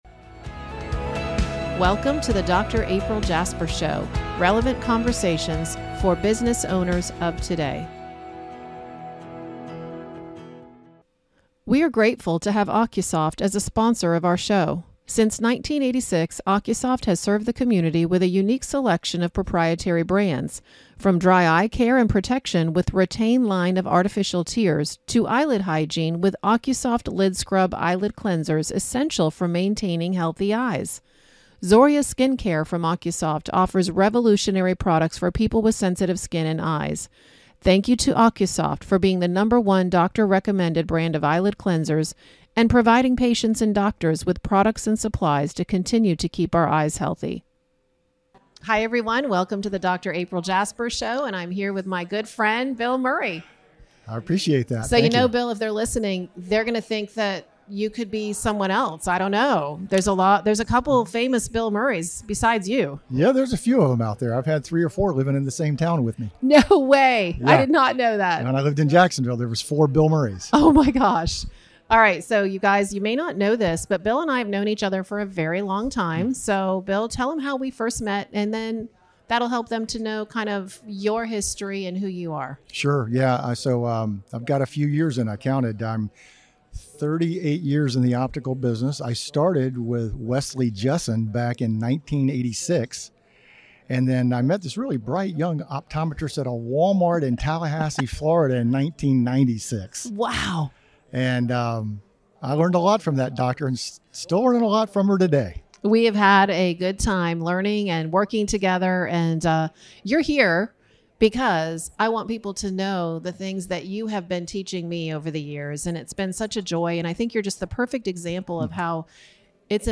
Tune in for a heartfelt conversation with one of the most skilled glaucoma specialists I know—this is one you won’t want to miss!…